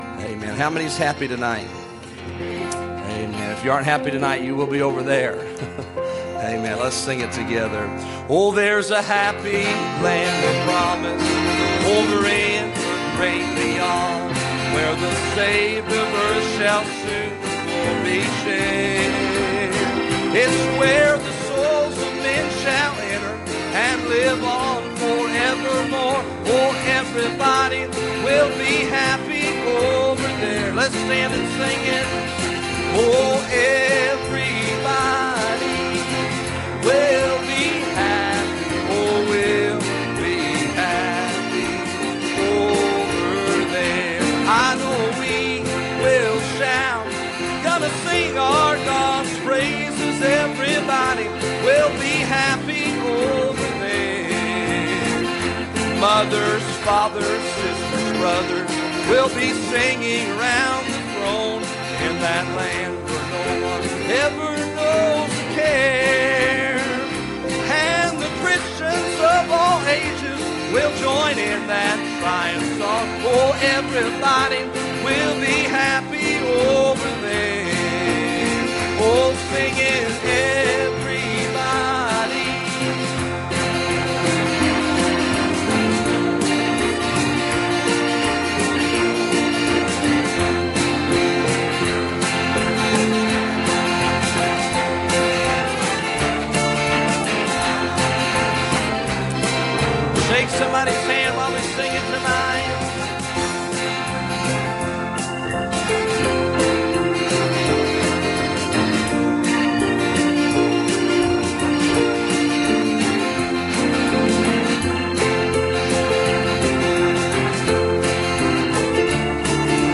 Passage: Hebrews 11:17 Service Type: Wednesday Evening